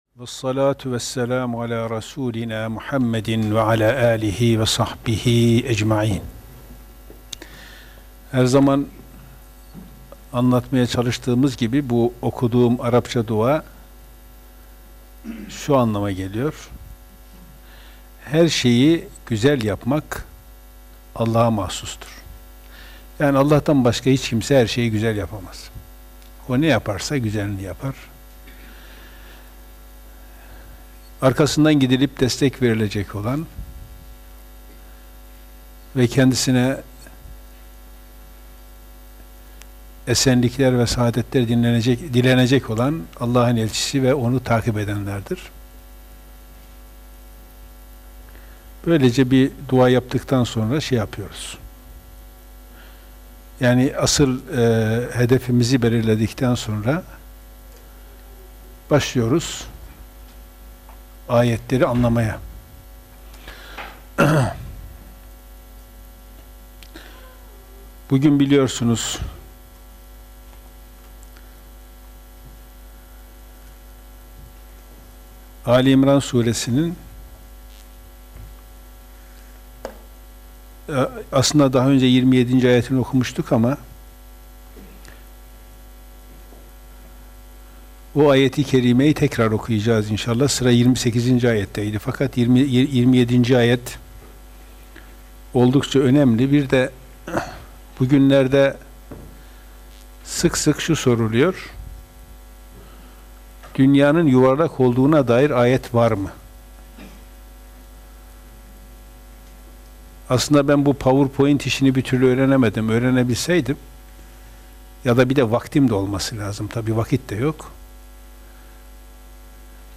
Gösterim: 3.403 görüntülenme Kur'an Sohbetleri Etiketleri: ali-imran suresi 27. ayet > kuran sohbetleri Elhamdü lillahi rabbil alemin.